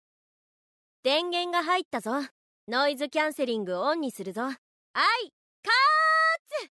Suara Navigasi Built-In dalam TWS
Sayangnya inilah yang membuat saya menjual Buds 2 Pro saya, hanya demi mendengarkan suara Ran yang di Aikatsu disuarakan oleh Ayaka Ohashi setiap hari ~
Perasaan gembira langsung muncul, karena rasanya seperti mendengarkan ASMR Hasshi secara langsung, dan setiap harinya karena suaranya akan terputar secara otomatis setiap kali saya memasang earphone.